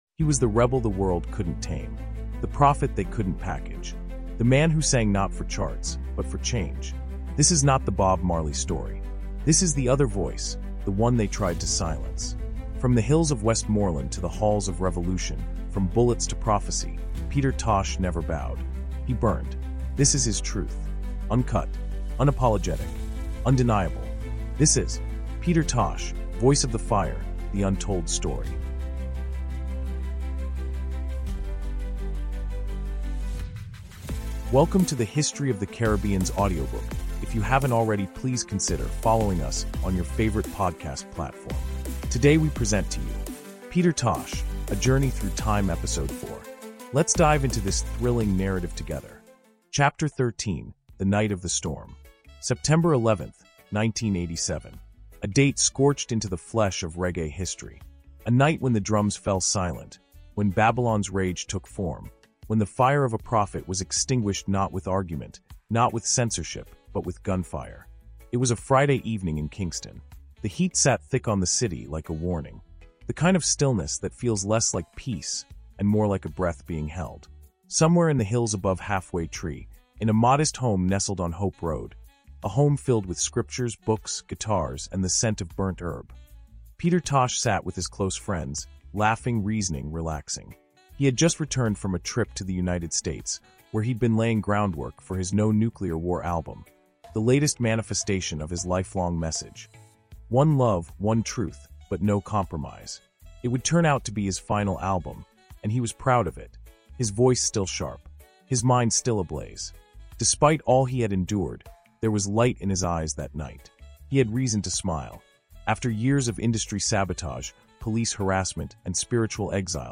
Peter Tosh: Voice of the Fire – The Untold Story is a gripping 16-chapter cinematic audiobook that resurrects the life, music, and martyrdom of one of reggae’s fiercest revolutionaries. From his rise with The Wailers to his assassination in 1987, this raw and emotionally charged narrative exposes the industry sabotage, political conspiracies, and spiritual battles behind the man who refused to bow. Featuring detailed storytelling written for AI narration, this audiobook is more than a biography—it’s a rebellion in words.